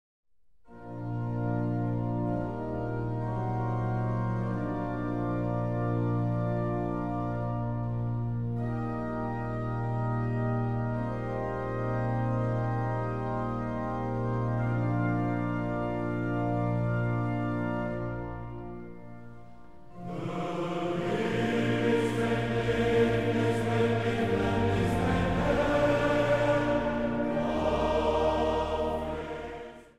orgel
Zang | Mannenkoor